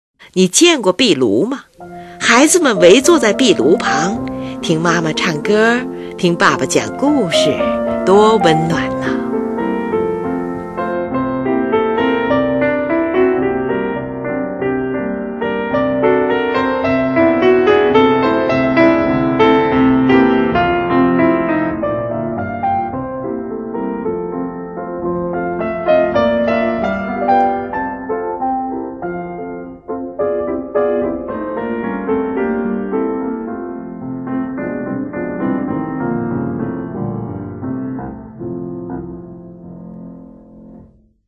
乐曲始终保持着柔和的节奏，旋律纯朴、安详，充满了和谐的气氛